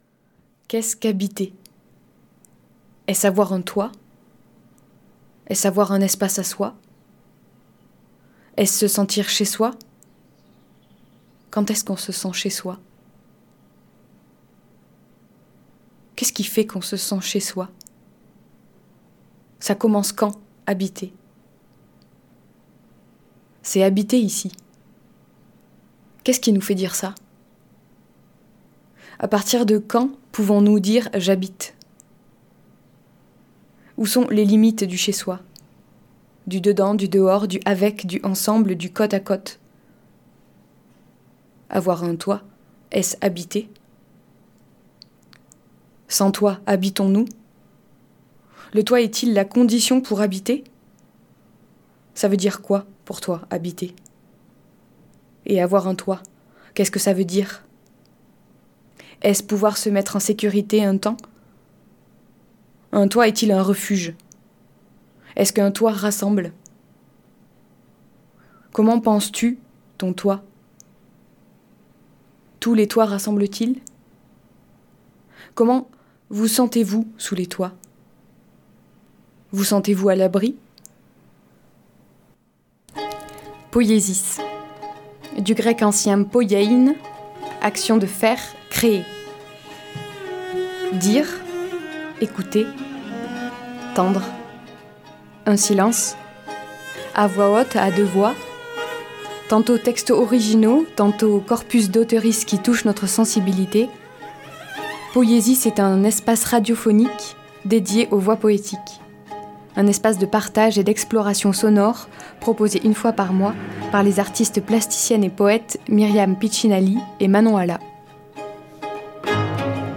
Emission du samedi 5 avril à 13h (rediffusion dimanche 19h)
Dire, écouter, tendre – un silence, à voix haute, à deux voix ; tantôt textes originaux, tantôt corpus d’auteur.ices qui touchent notre sensibilité. Poïesis est une espace radiophonique dédié aux voix poétiques.